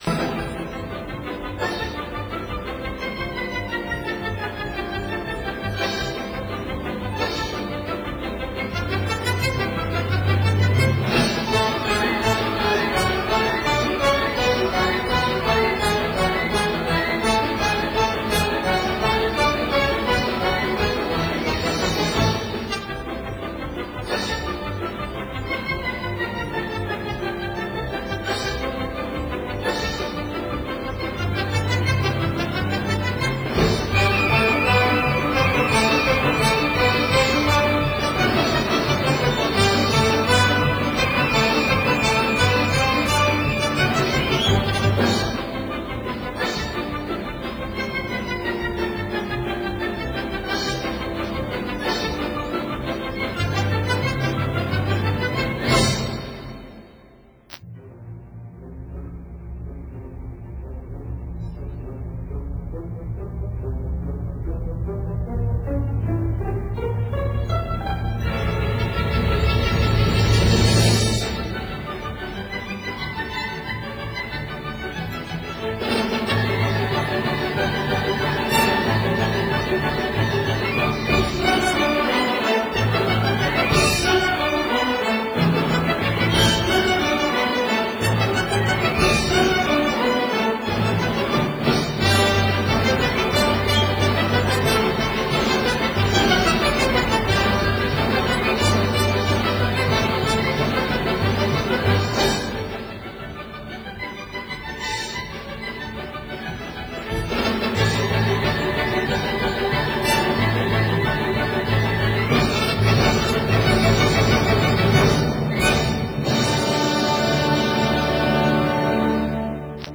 ballet